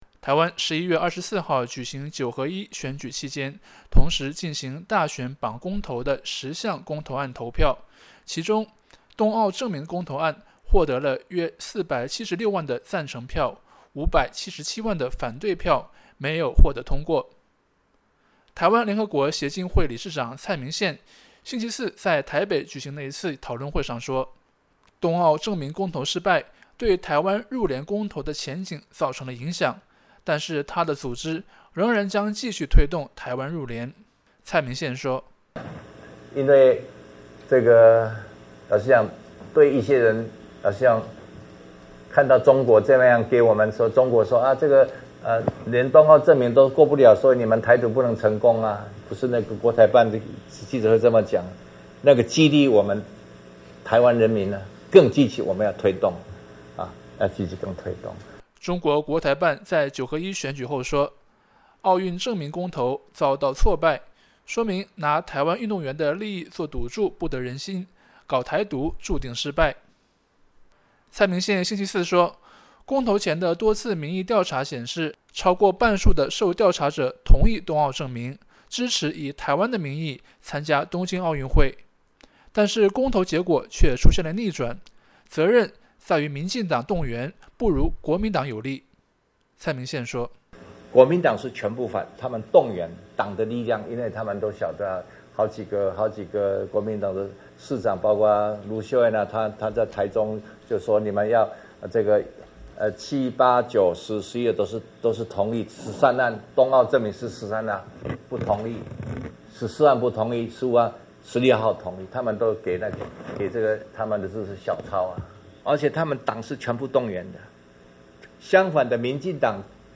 台湾联合国协进会理事长蔡明宪星期四在台北举行的一次讨论会上说，东奥正名公投失败对台湾入联公投的前景造成了影响，但他的组织仍将继续推动台湾入联。